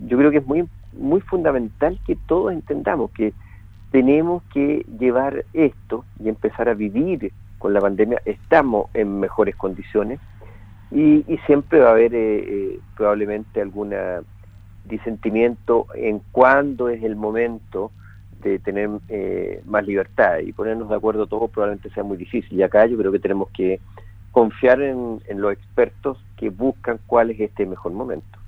En conversación con Radio Sago, el Seremi de Salud de la Región de Los Lagos, Alejandro Caroca se refirió a la presencia de la variante Delta del Covid-19 en el país, puntualizando que todos los virus sufren mutaciones con mayor o menor letalidad y mayor o menor grado de contagio.